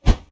Close Combat Swing Sound 82.wav